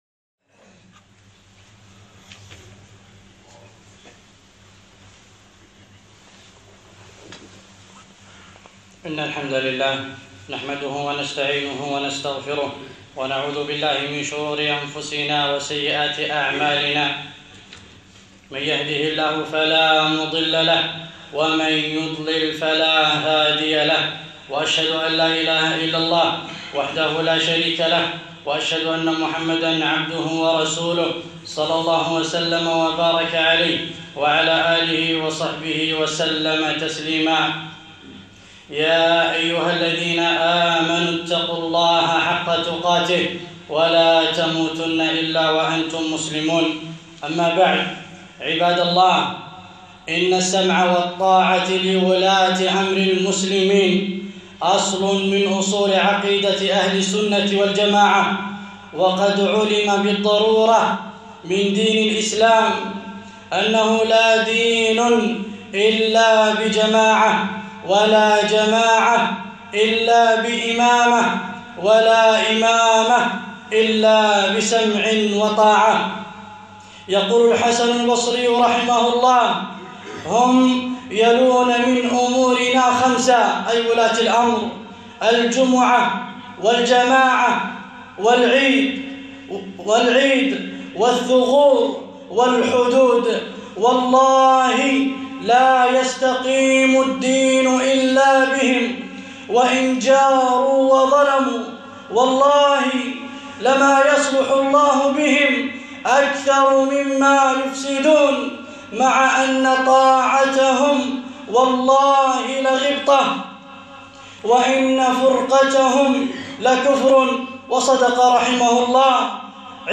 خطبة - الكويت إسلامية